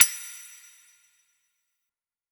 Metallic MadFlavor 1.wav